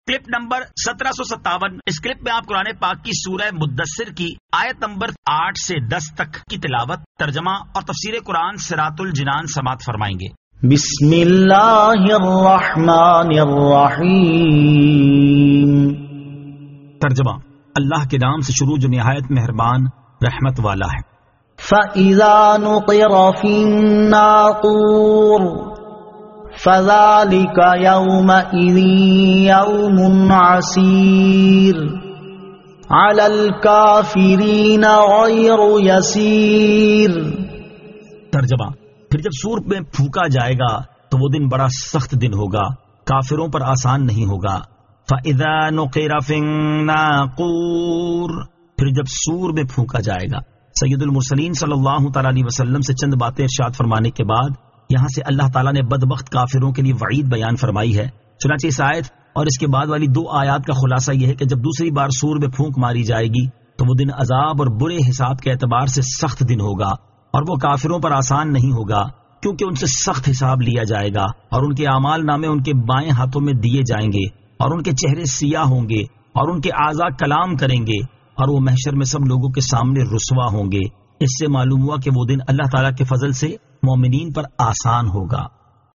Surah Al-Muddaththir 08 To 10 Tilawat , Tarjama , Tafseer